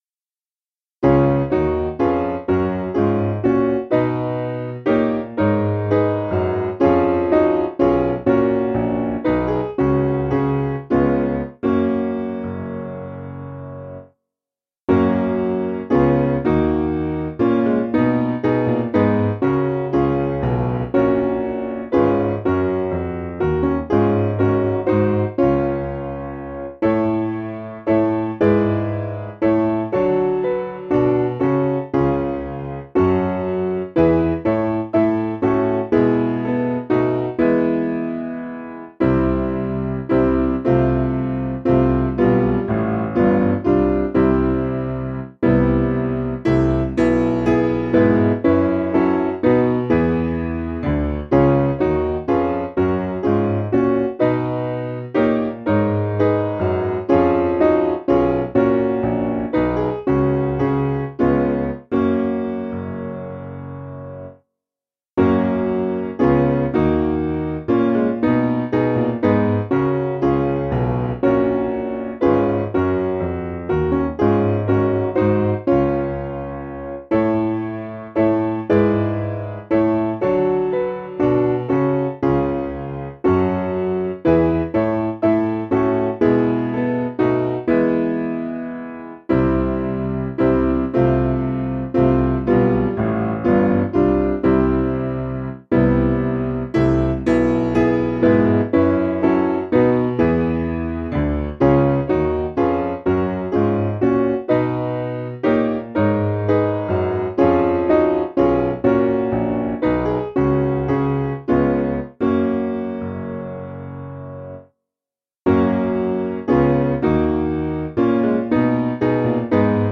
8.7.8.7.D
Simple Piano